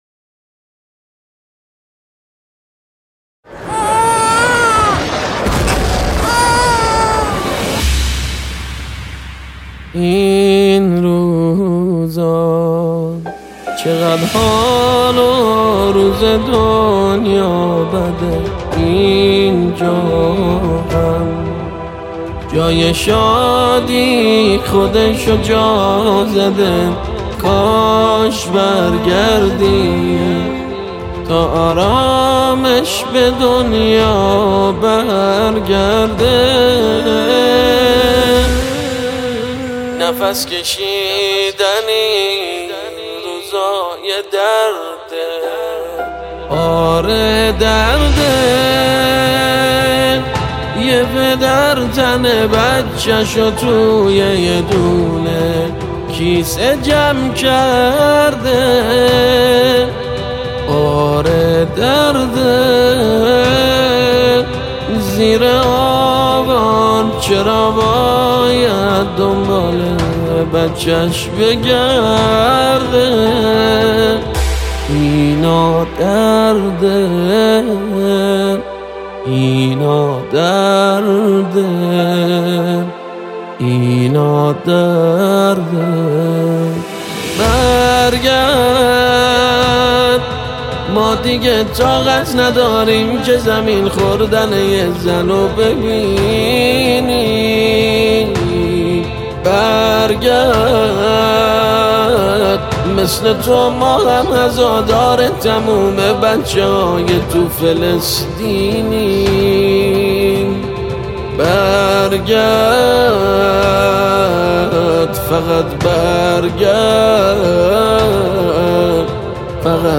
نماهنگ مهدوی